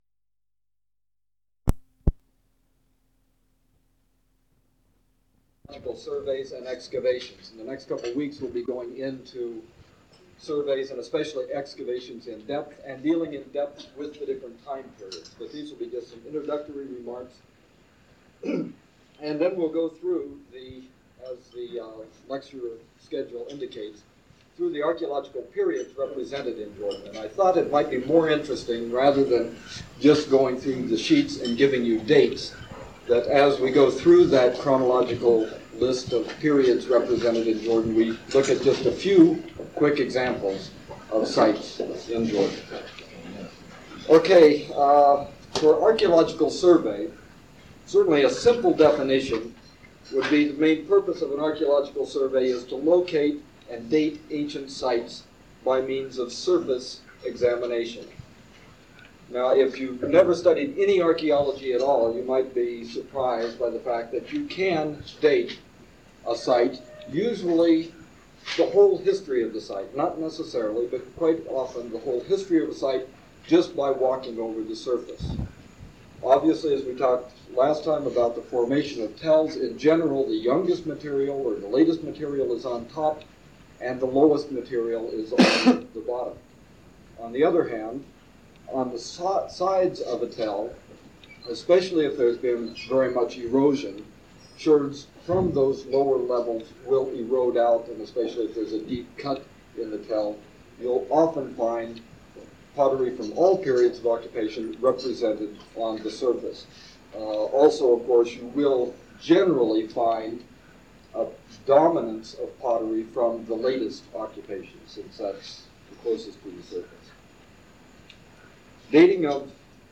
Archaeological Methodology - Lecture 3: Archaeological Periods Represented in Jordan